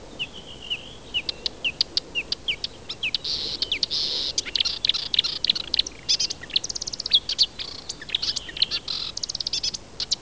Камышевка болотная (Acrocephalus palustris) - 1
Acrocephalus-palustris-1.wav